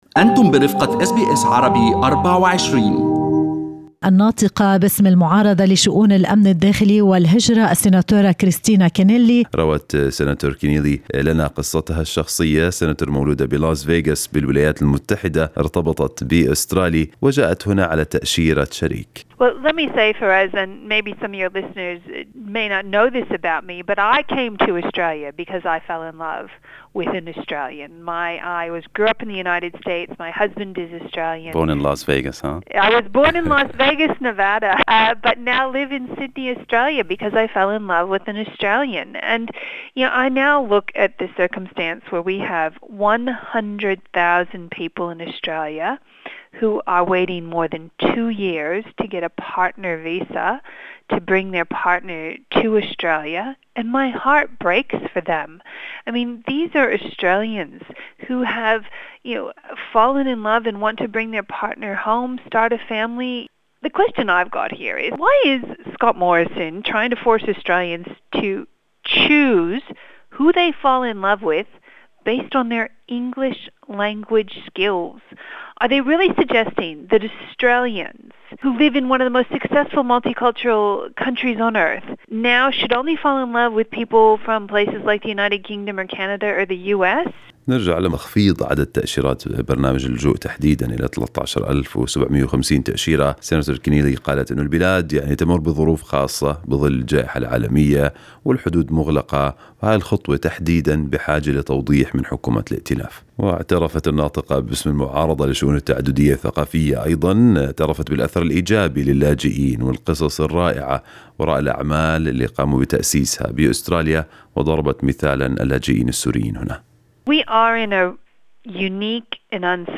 الناطقة باسم المعارضة لشؤون الأمن الداخلي في حديث خاص لأس بي أس عربي24